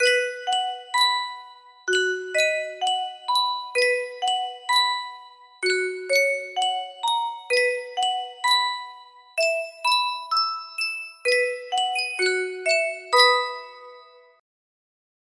Sankyo Music Box - Good Night Ladies CEJ music box melody
Full range 60